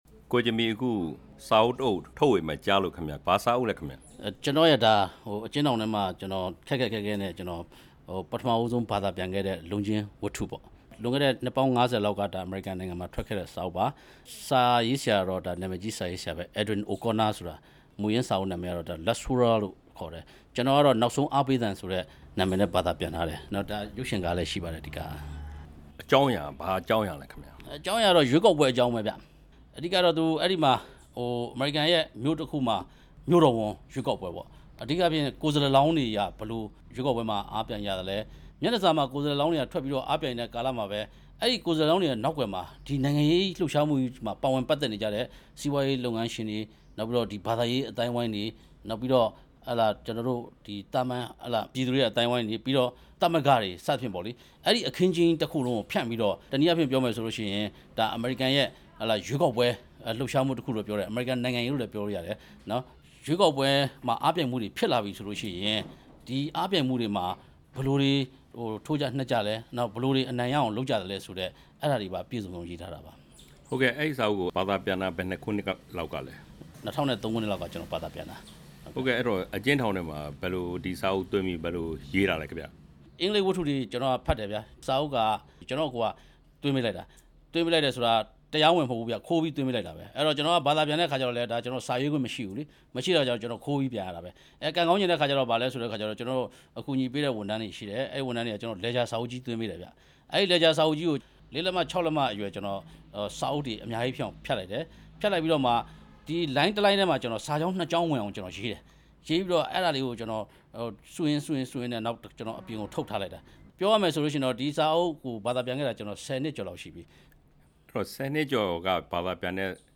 ၈၈ မျိုးဆက် ကိုဂျင်မီရဲ့စာအုပ်သစ်အကြောင်း မေးမြန်းချက်